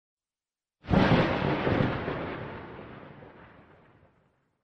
thunder.opus